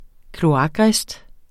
Udtale [ kloˈɑgˌʁεsd ]